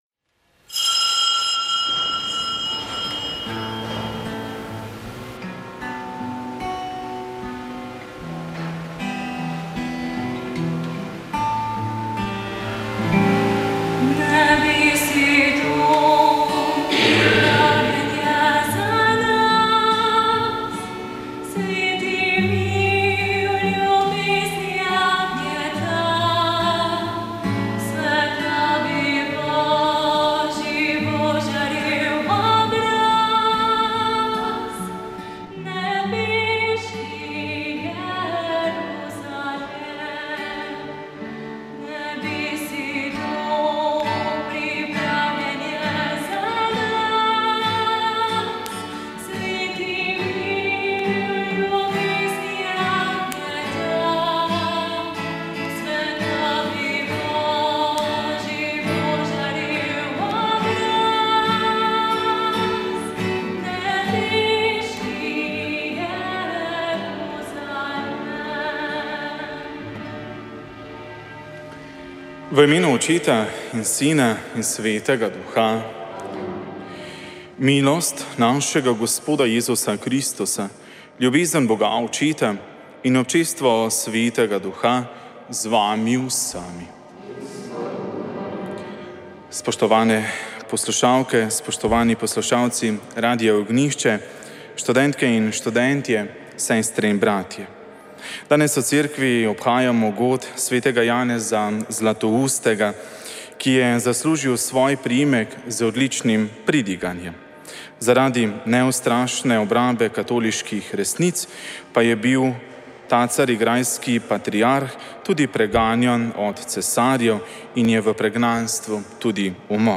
Sveta maša
Ob dnevu državnosti smo prenašali sveto mašo iz Arburetuma Volčji potok, kjer je potekalo srečanje Prijateljev radia Ognjišče.